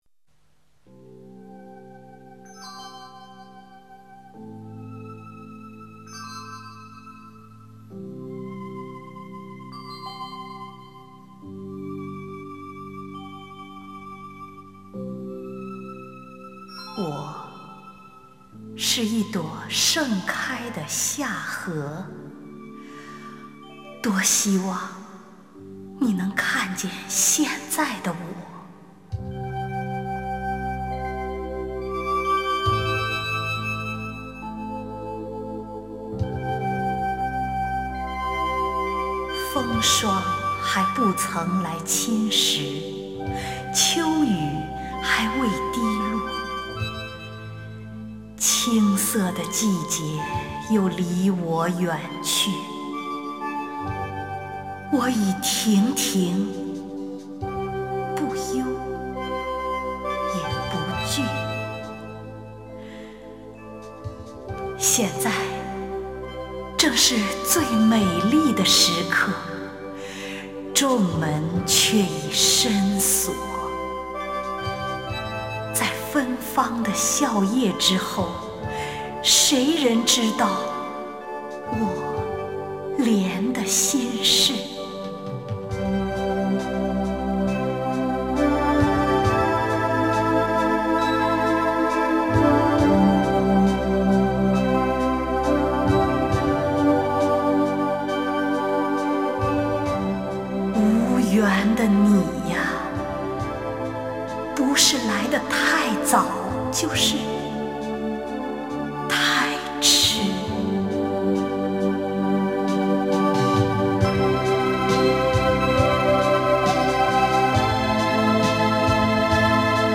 首页 视听 名家朗诵欣赏 丁建华
丁建华朗诵：《莲的心事》(席慕容)